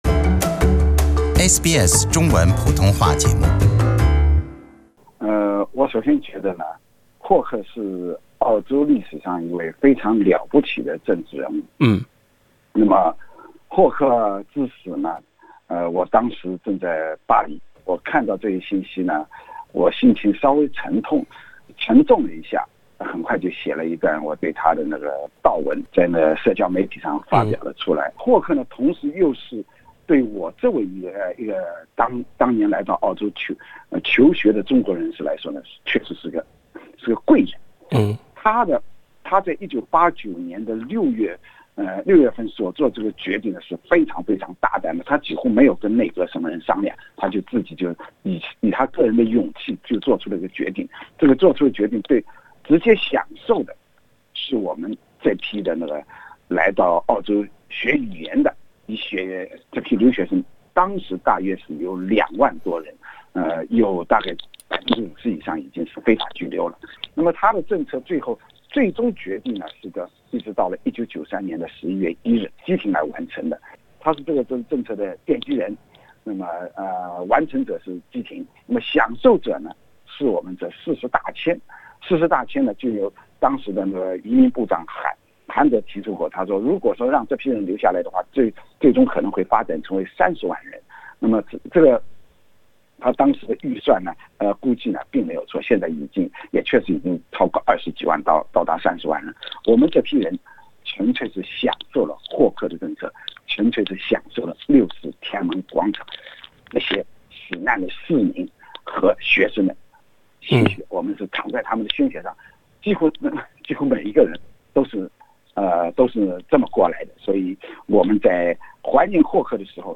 以上采访仅为嘉宾观点，不代表本台立场。